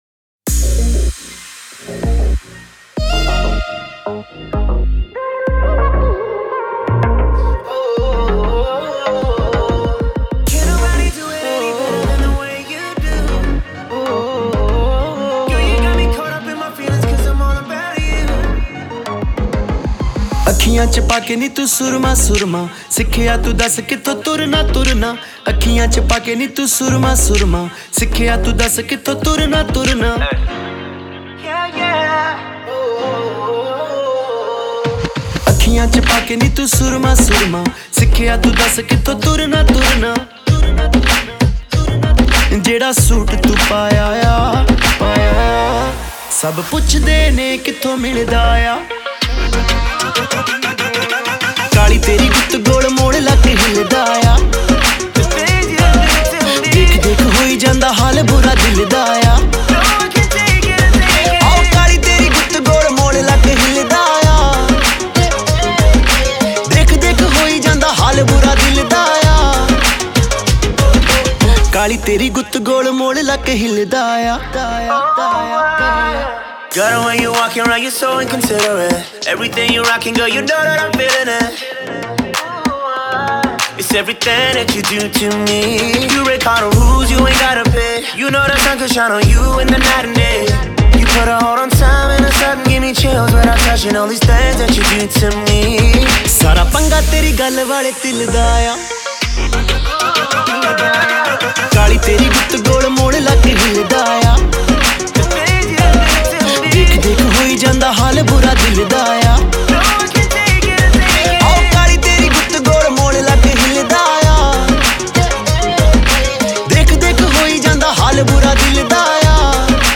Punjabi Bhangra